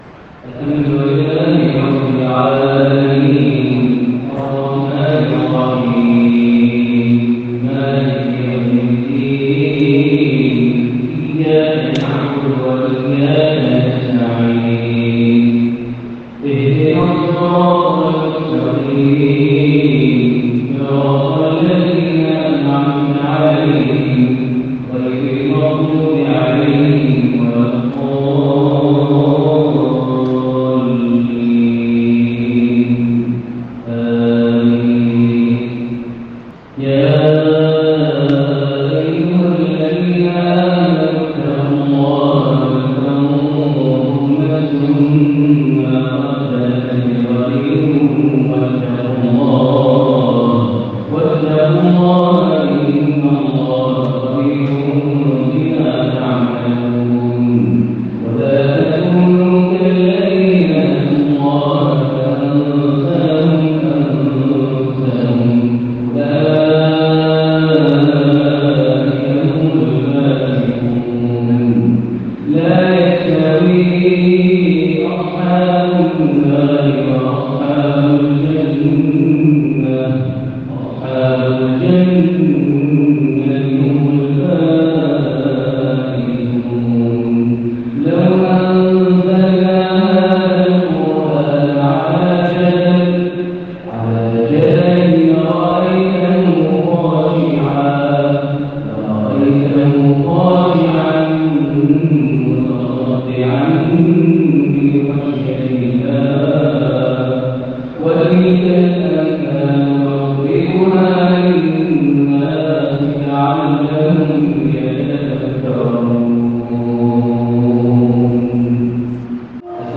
من الحرم | عشائية بالكرد تسلب الألباب من سورة الشعراء ١٩ شوال ١٤٤١هـ (69-104) > 1441 هـ > الفروض - تلاوات ماهر المعيقلي